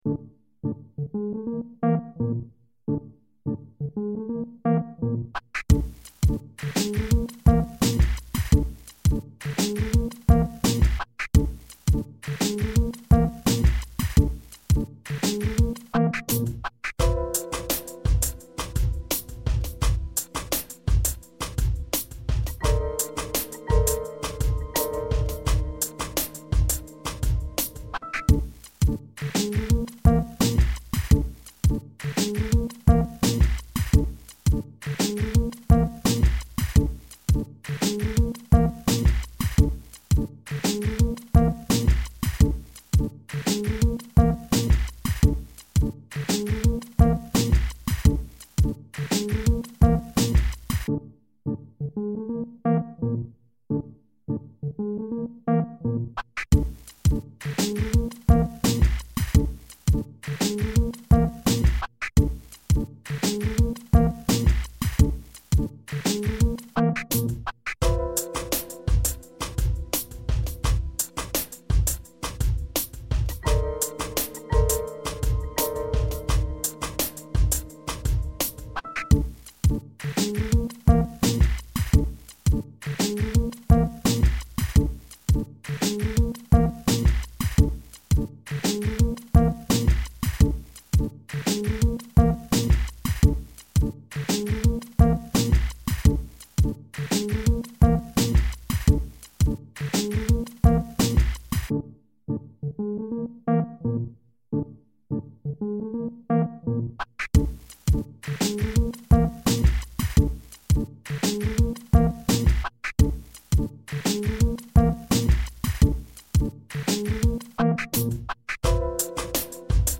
Instrumental...